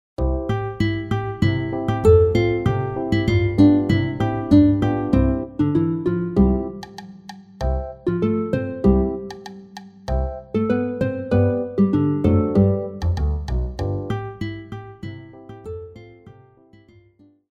RÉPERTOIRE  ENFANTS